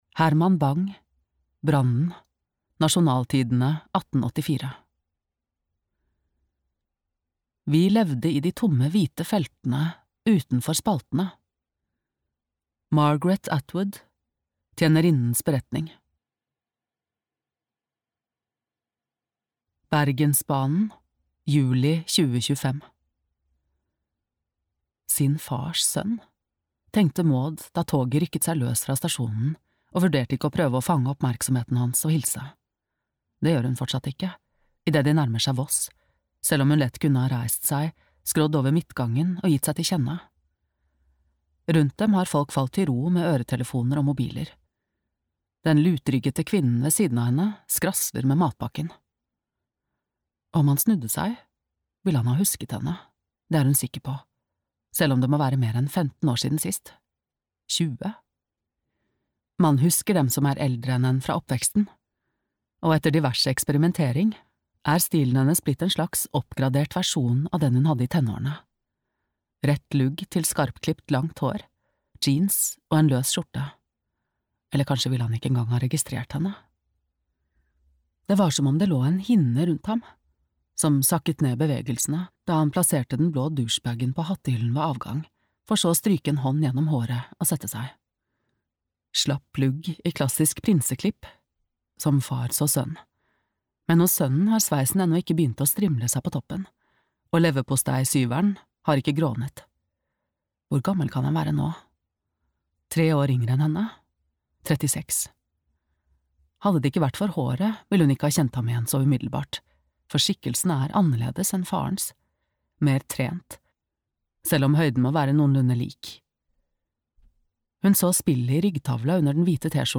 I hvalens buk - roman (lydbok) av Hilde Rød-Larsen